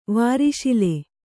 ♪ vāri śile